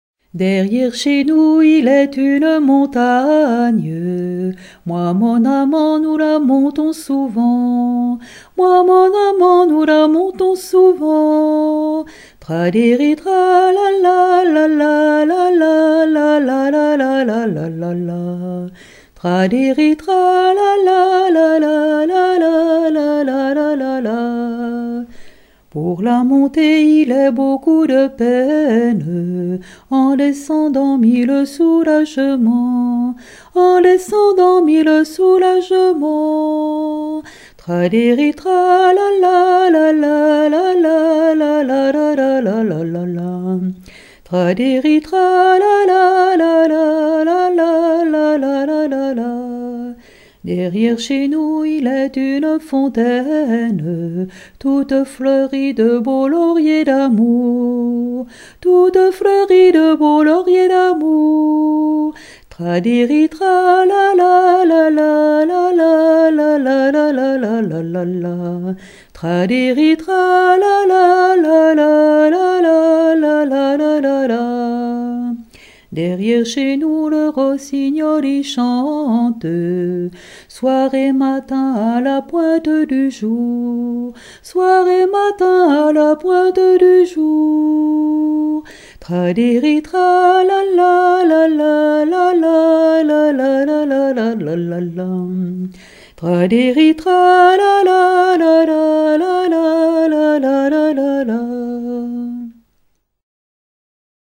- II, E-08 Coirault : Derrière chez nous il y a une montagne ou Les amoureux sont toujours malheureux - 000120 Thème : 0001 - La poésie - Lyriques Résumé : Différents débuts mais conclusion : Les amoureux sont toujours malheureux.
Genre strophique
Pièce musicale éditée